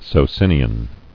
[So·cin·i·an]